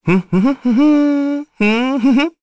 Neraxis-Vox_Hum_kr.wav